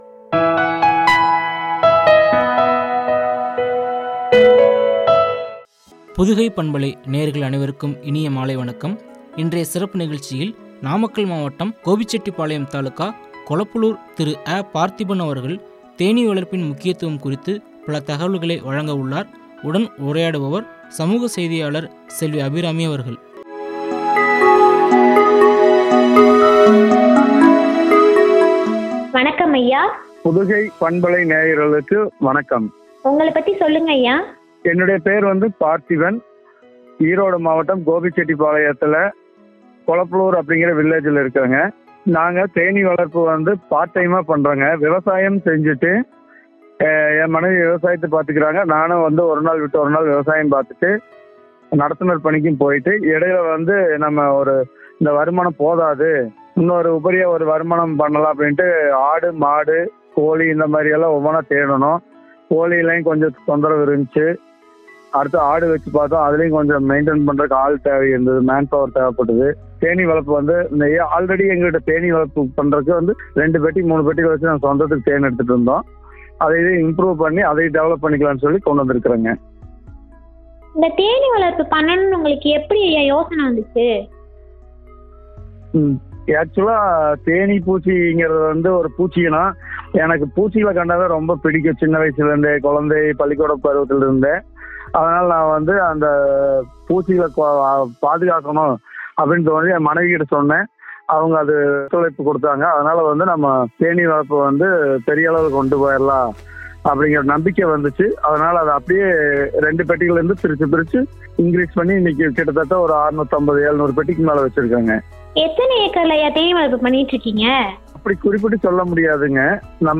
தேனி வளர்ப்பின் முக்கியத்துவம் பற்றிய உரையாடல்.